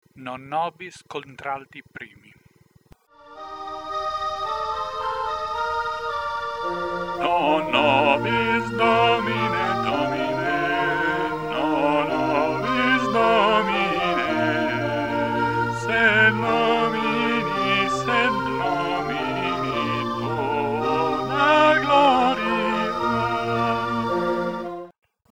Non nobis - Contralti 1 + Base.mp3